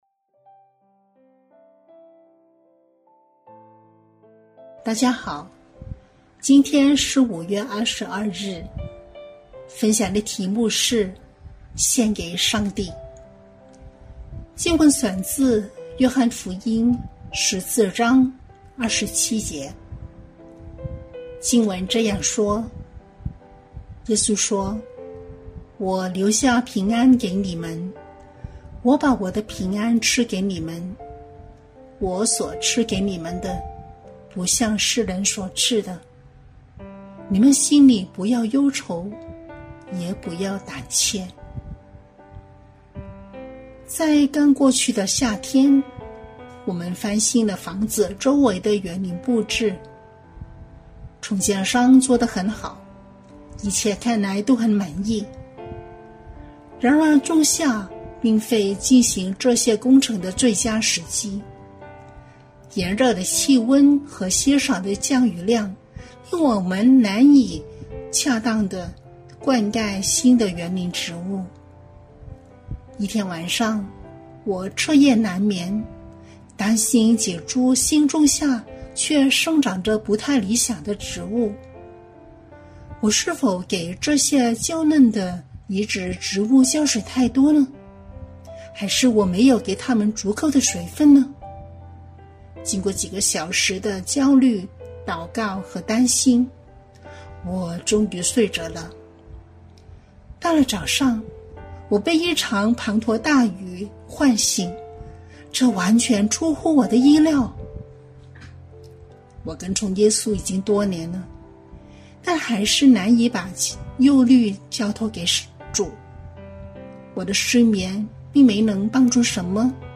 錄音員